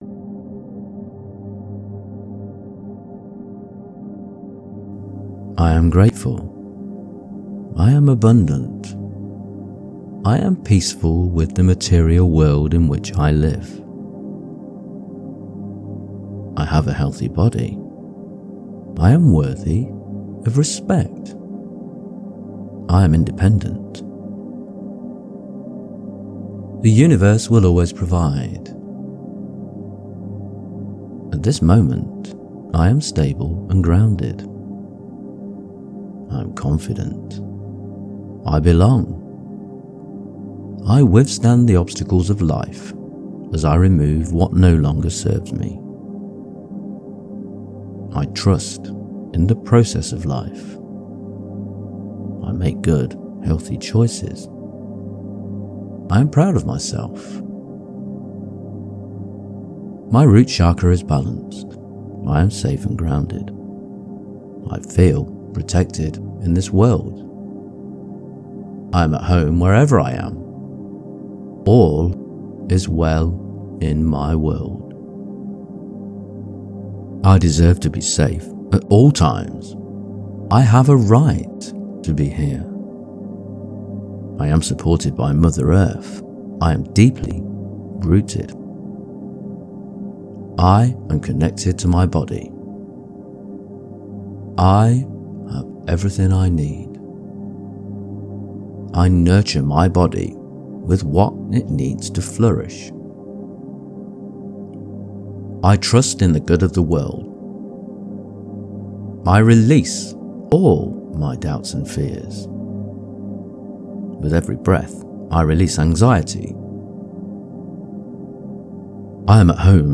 396-affirmations.mp3